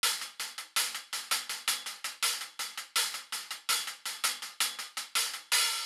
Old Western HiHat Loop.wav